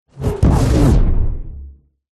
На этой странице собраны звуки, ассоциирующиеся с черной магией: загадочные шёпоты, ритуальные напевы, эхо древних заклинаний.